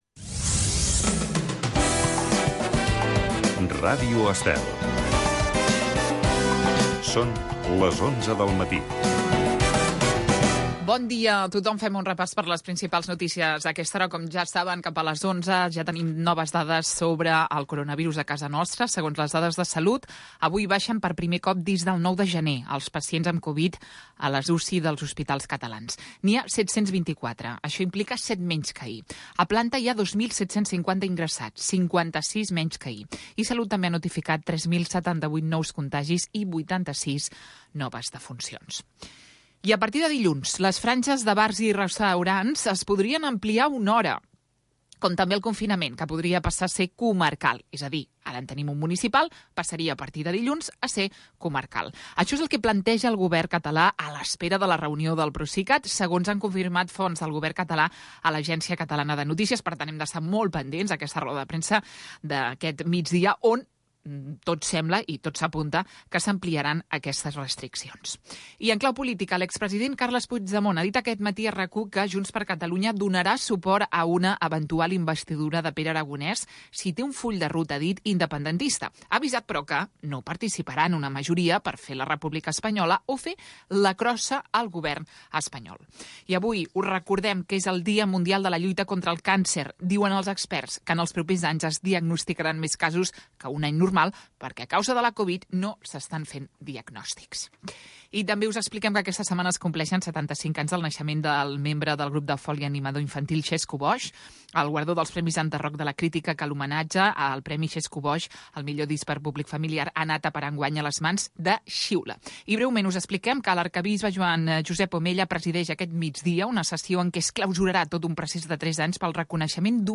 Espai amb d'entrevistes als protagonistes més destacats de cada sector, amenitzat amb la millor música dels 60, 70, 80 i 90